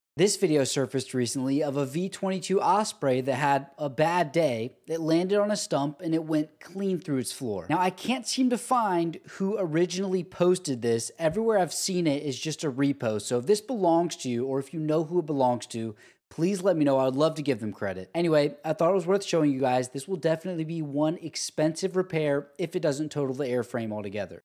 V 22 Osprey Lands On Stump Sound Effects Free Download